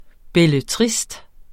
Udtale [ bεləˈtʁisd ]